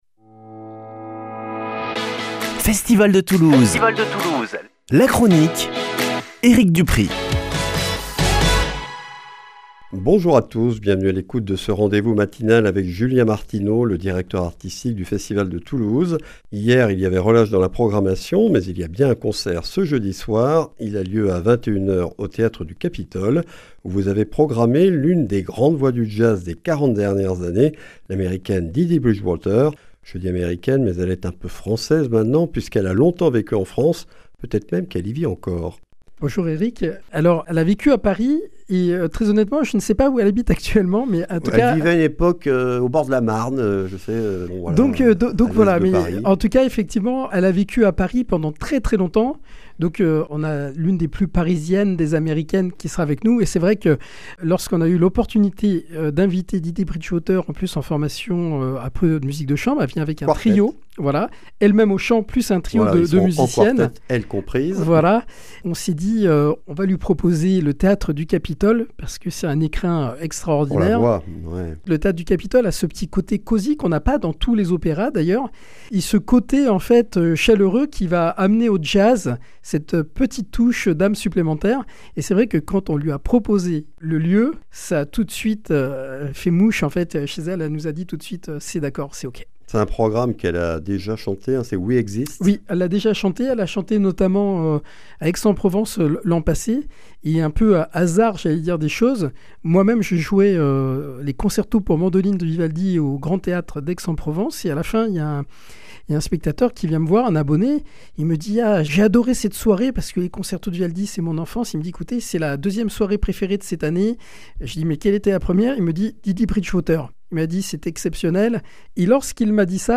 Chronique Festival de Toulouse du 3 juillet 2025